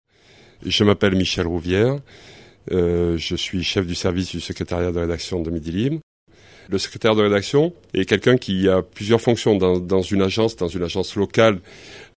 extrait d'interview (96 Ko)